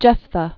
(jĕfthə)